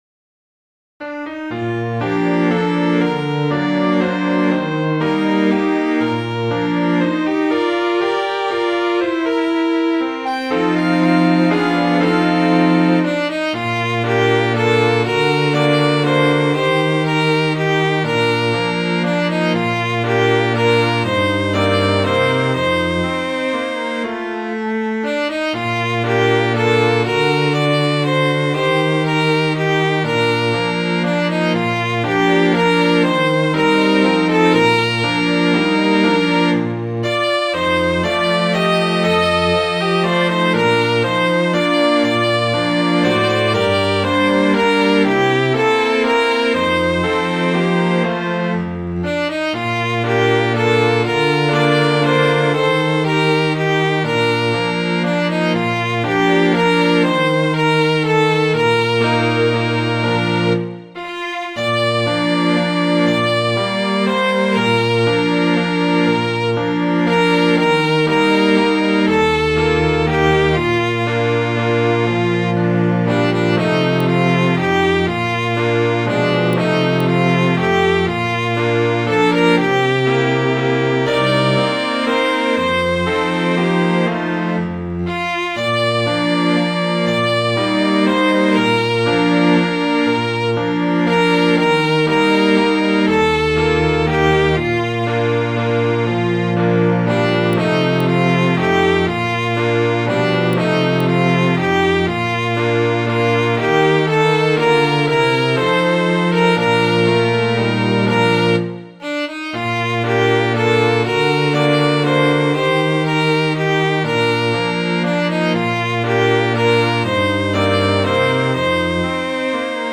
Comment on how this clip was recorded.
wildrose.mid.ogg